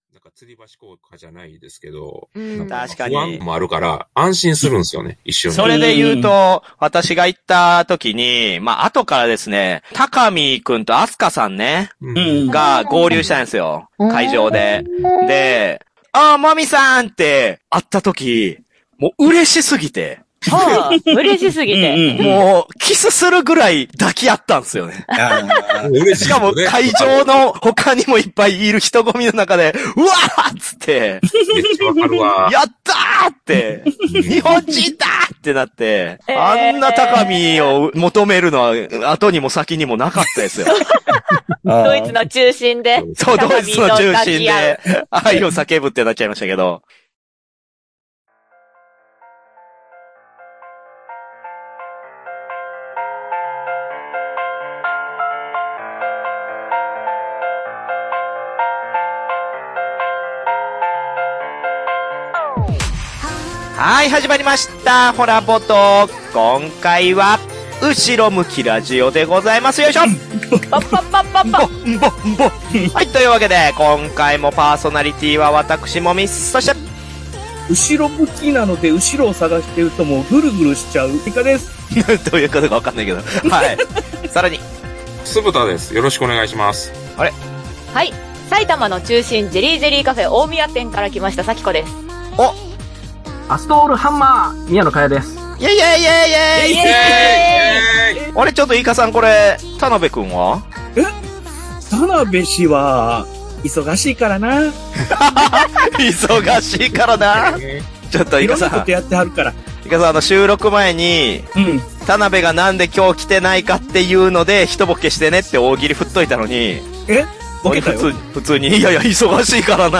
ほらボド！ - 第545夜オンライン収録「エッセン・シュピールに行かない理由」
※Zoomによるオンライン収録のため、 途中聴き取り辛い点が多々あります。